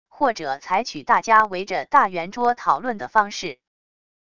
或者采取大家围着大圆桌讨论的方式wav音频生成系统WAV Audio Player